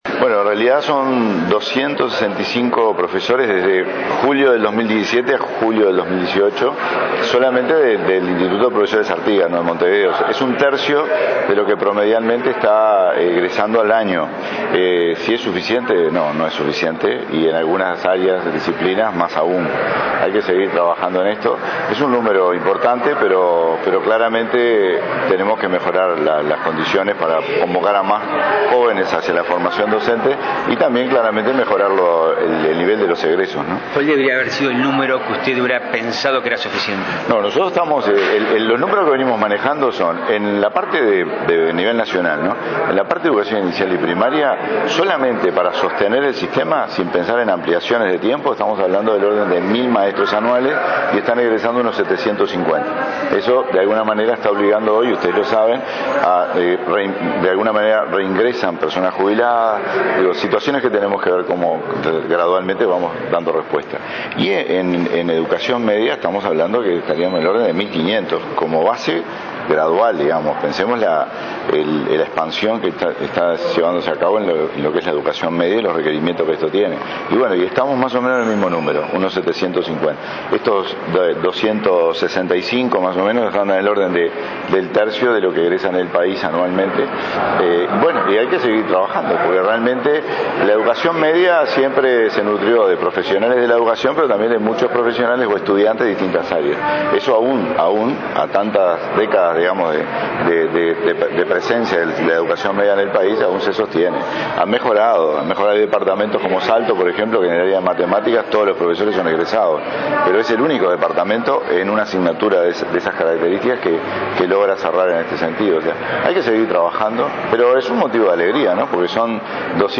Doscientos sesenta y cinco estudiantes egresados del IPA recibieron los títulos habilitantes para el ejercicio de la docencia en educación media a partir de 2019, en un acto realizado este viernes en el teatro Solís. El presidente del Codicen, Wilson Netto, subrayó que para Primaria se necesita que egresen unos 1.000 docentes por año y lo hacen 700, mientras que en Secundaria se necesitarían 1.500 y egresan 750.